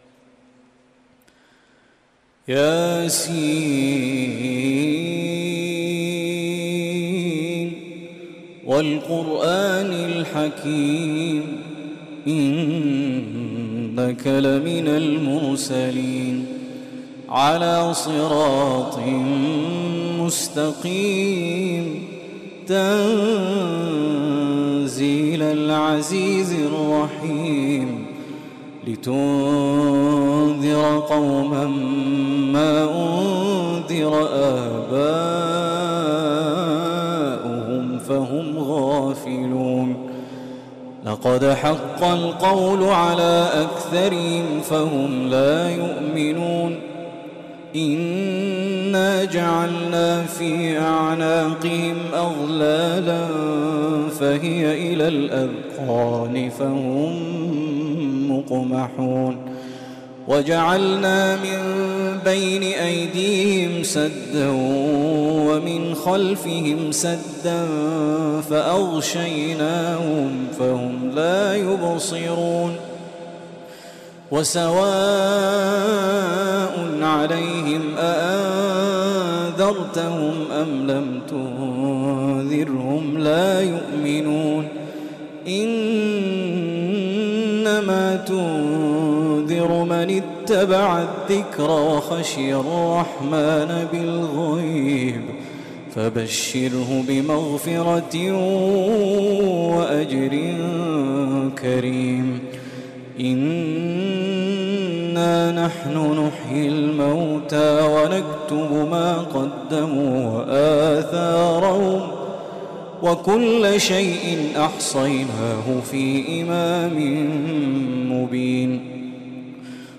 شنّف أذنيك بهذه التلاوة العذبة المتقنة
تلاوة من سورة يسٓ القارئ